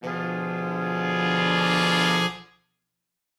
Index of /musicradar/gangster-sting-samples/Chord Hits/Horn Swells
GS_HornSwell-Bdim.wav